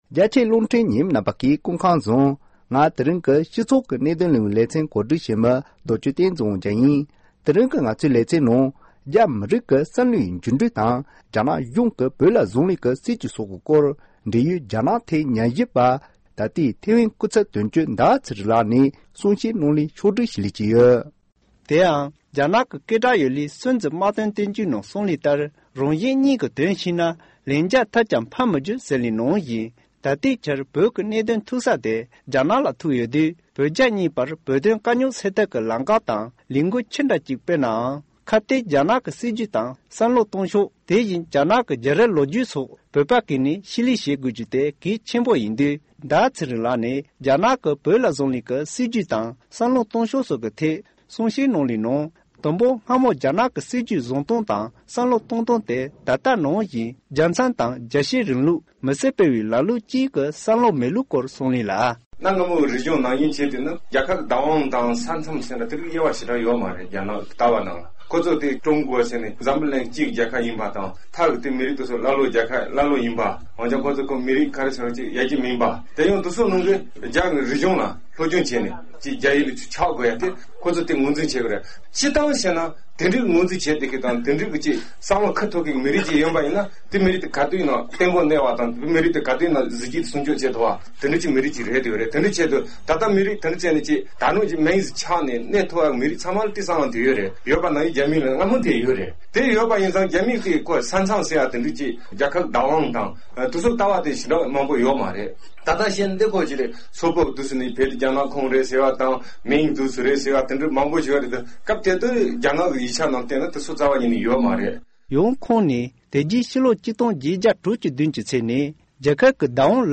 གསུང་བཤད